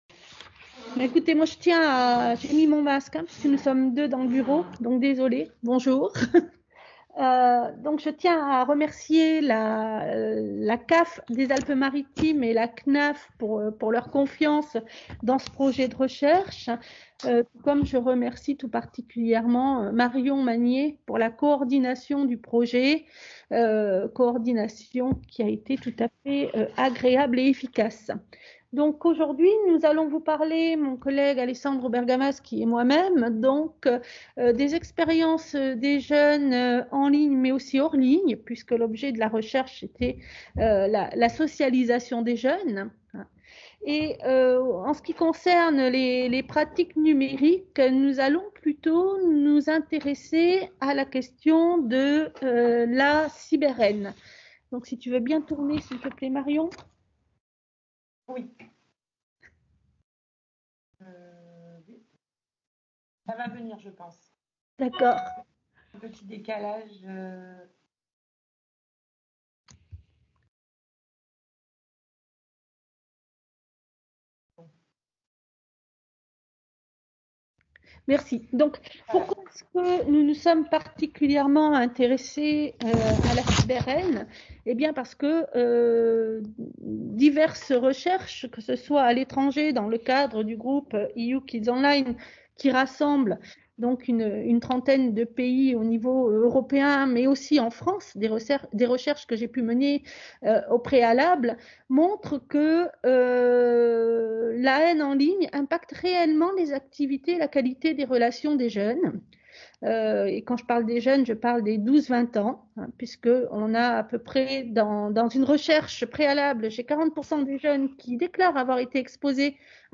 Enregistrement visioconférence.